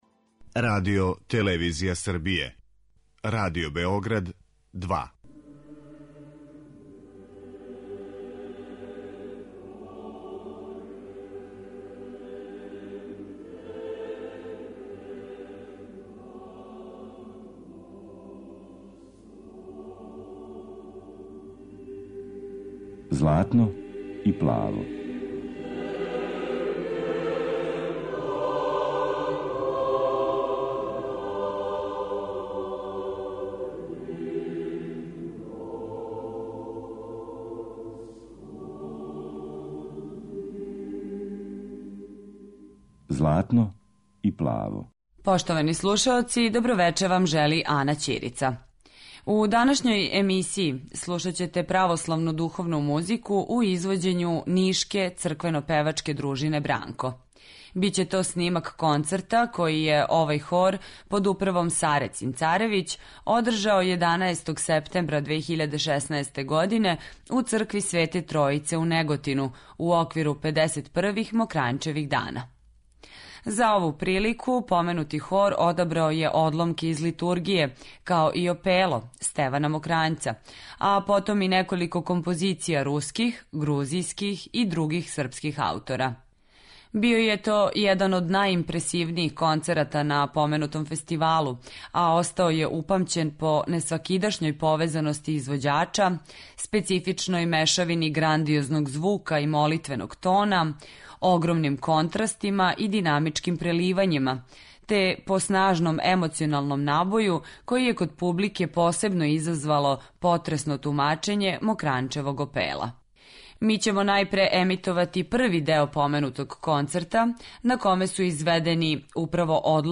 Емисија је по традицији посвећена православној духовној музици.